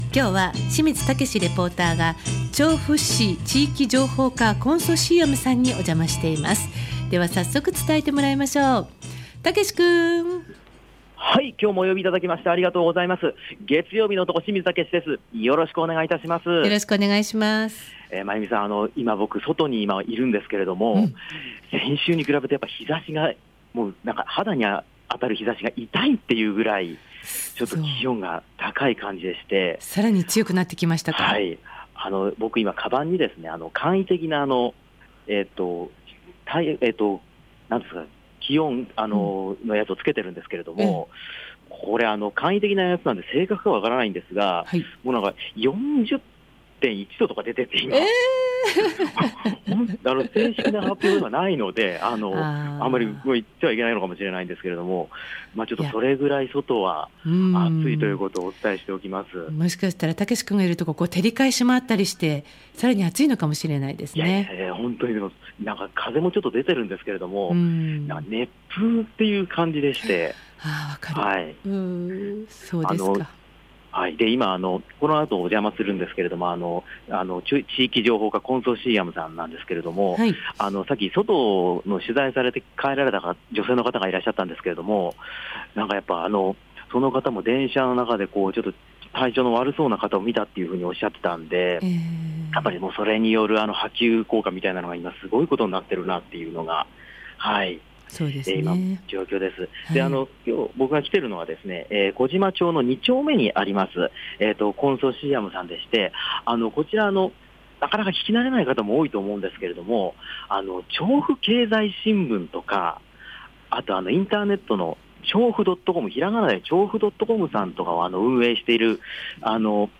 ちょうど、写真に写っている窓の向こう側・室内で、本日はお話を伺いました。